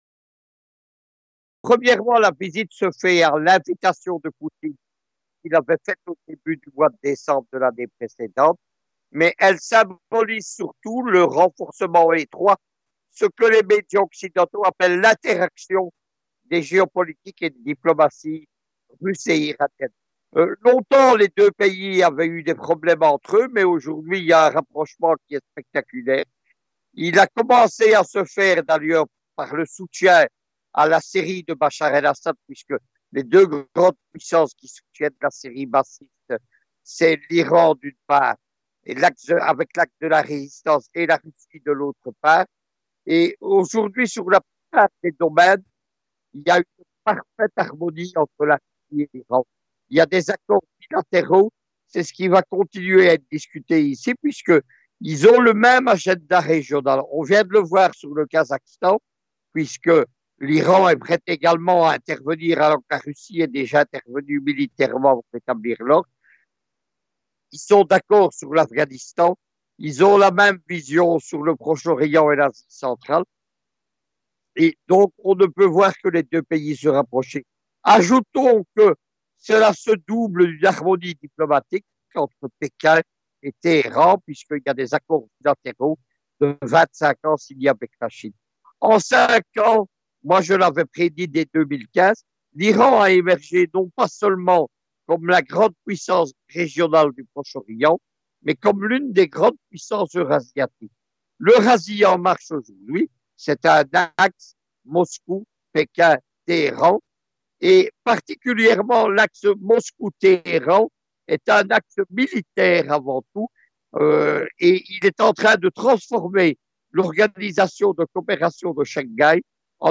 nous avons contacté le géopoliticien